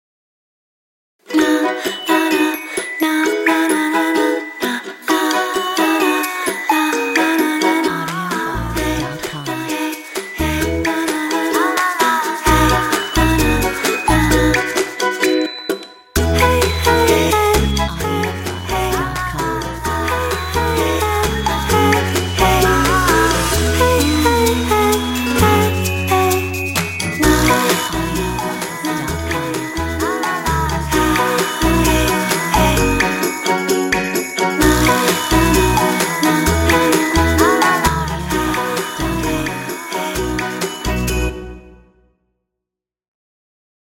Metronome 130